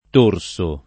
t1rSo] s. m. — sim. Torso, ant. nome it. di Tours, e il cogn. Torsi — non rara oggi in Tosc. una pn. con -o- aperto, dovuta all’attraz. di dorso (spec. in locuz. come a torso nudo: non invece dove torso sia sinon. di torsolo); ma più freq., in Tosc. e soprattutto fuori, l’attraz. in senso inverso